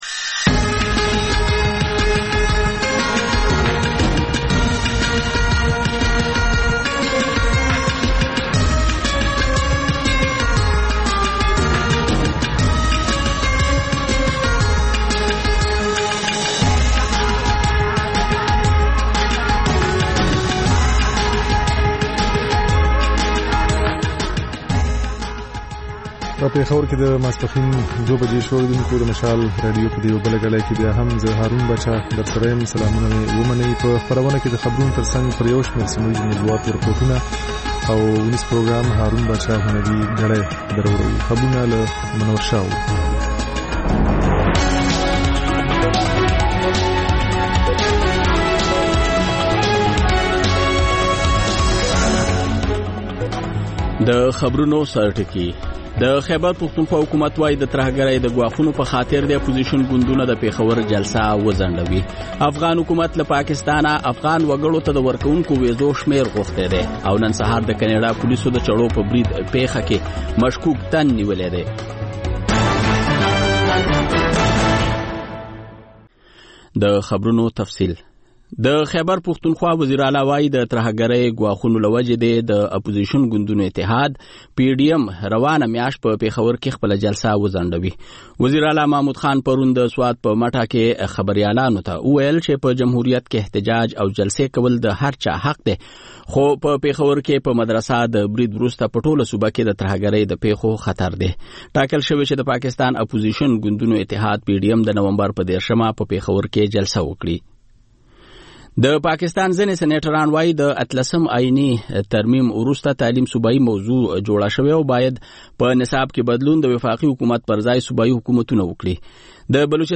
په دې خپرونه کې لومړی خبرونه او بیا ځانګړې خپرونه خپرېږي.